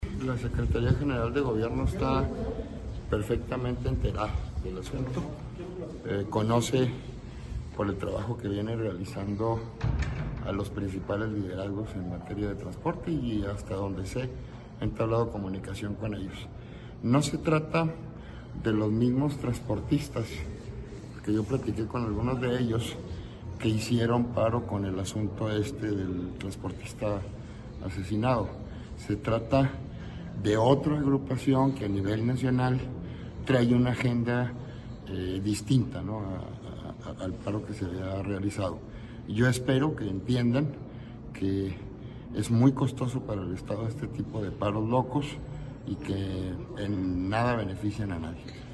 AUDIO: CÉSAR JÁUREGUI MORENO, FISCAL GENERAL DEL ESTADO